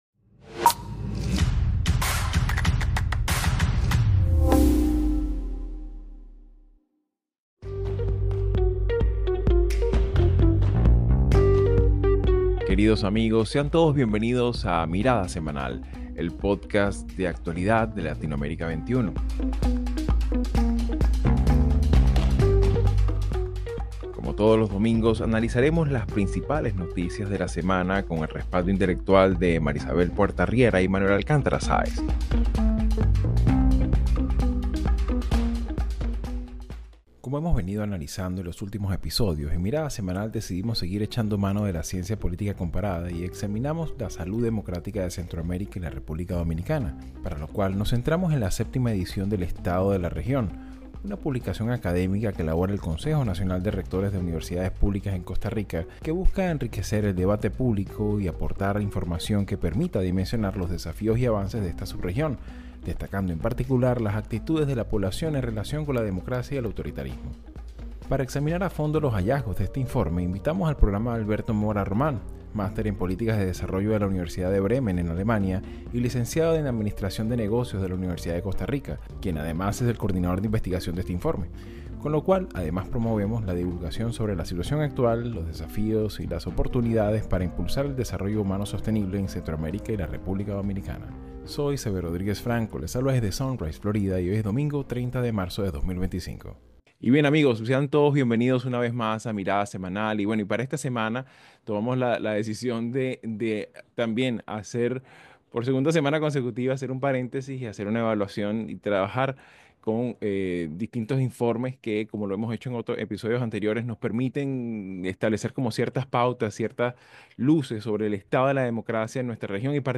Mirada Semanal | Entrevista